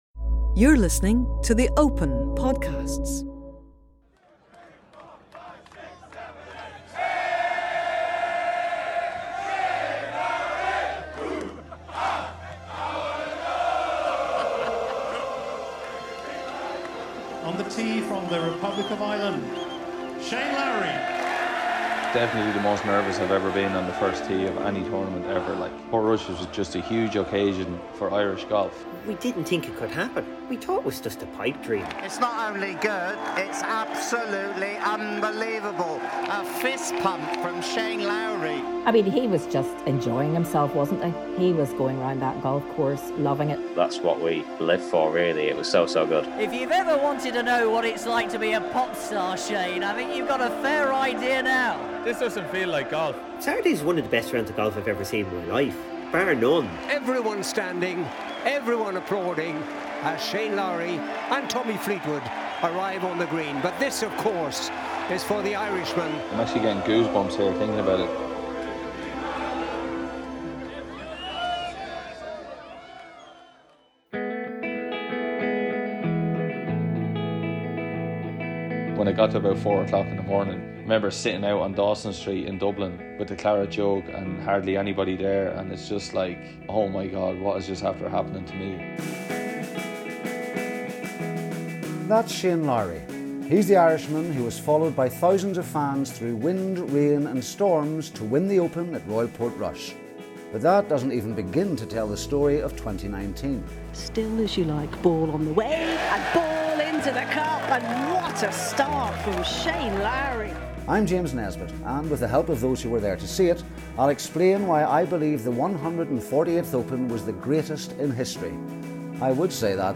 Highlights from Seasons 3 & 4 of The Story of The Open documentary series, produced by IMG Audio for The R&A.
In 2021, seven Opens were recounted through the perspective of those who lived every putt. With exclusive interviews, spine-tingling commentary and emotive narration from the likes of James Nesbitt, Di Stewart, Tommy Fleetwood and DJ Spoony, The Story of… immersed listeners in the tales of past summers.